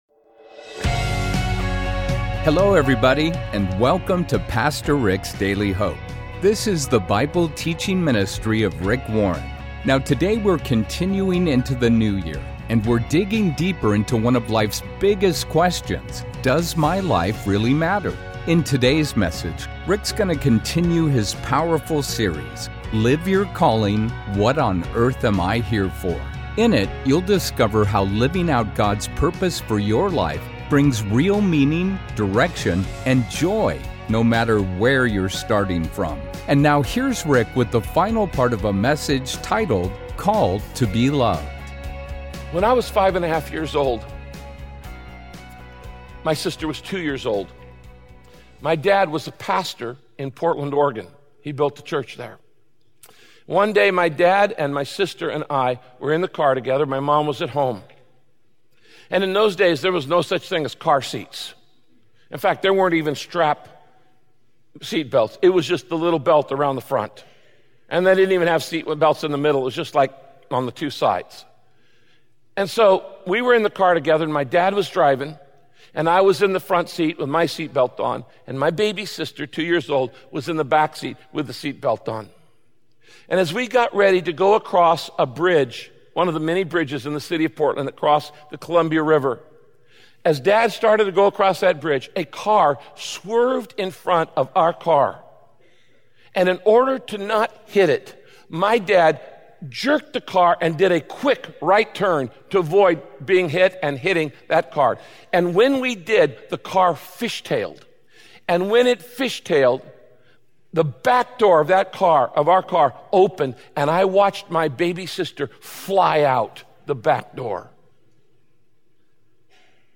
In this message, Pastor Rick explains just how much God loves you and why the spiritually lost are worth God's rescue mission.